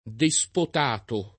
despotato [ de S pot # to ]